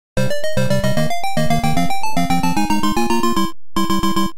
Звук и музыка главной темы из игры